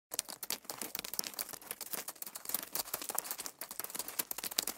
Add Spider Footstep Sound
spider_loop.ogg